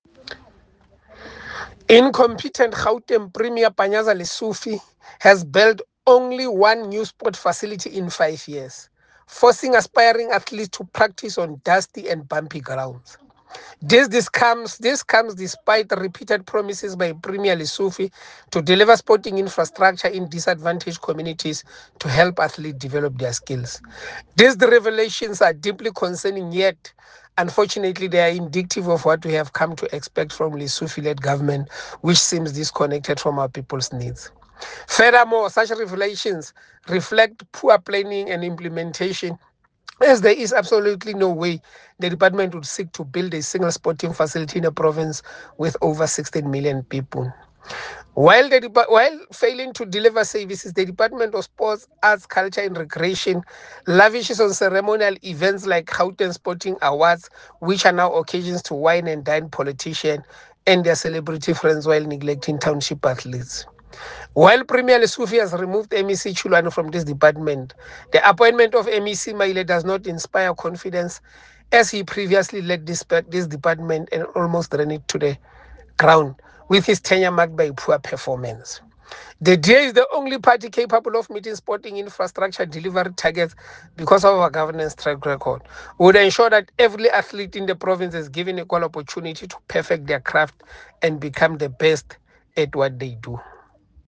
Note to Editors: Attached please find a soundbite in English by Kingsol Chabalala MPL